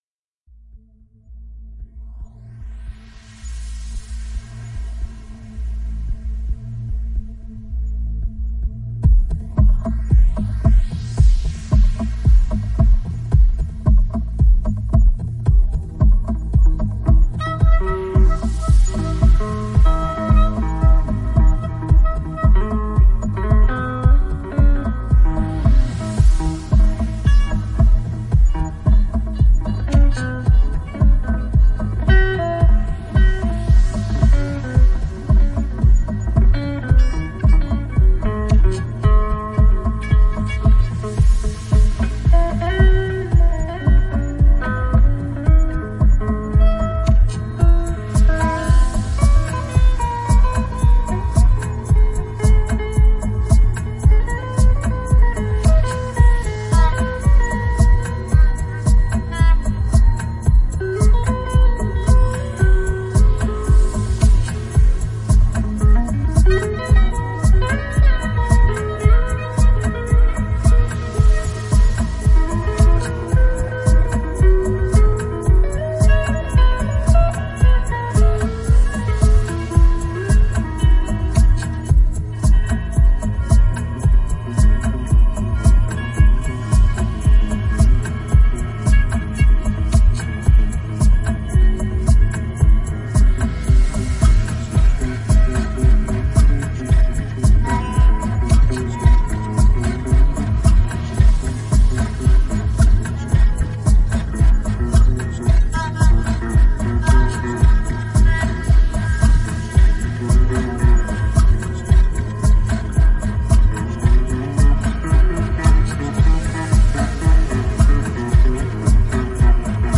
Recorded live @ Riserva San Settimio (Palazzo Arcevia)
voice + natural sound base noise